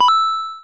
coin_short.wav